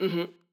VO_ALL_Interjection_14.ogg